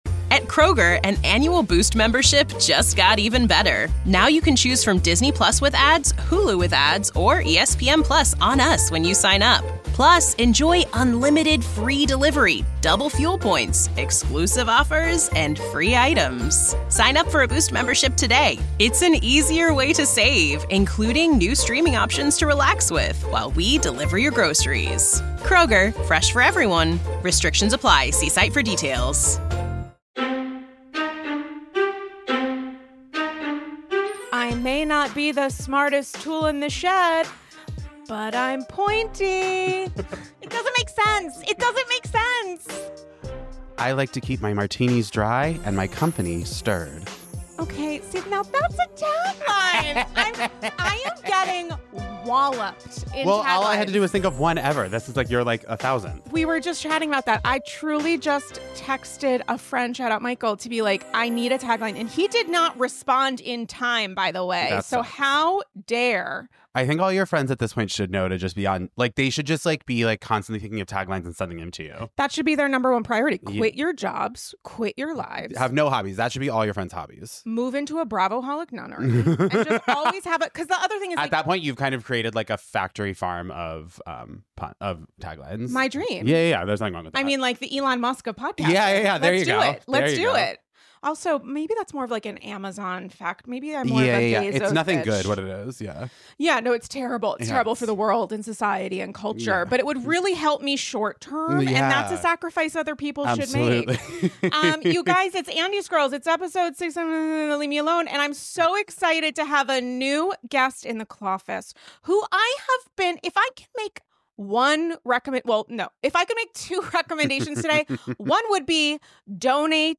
Reality TV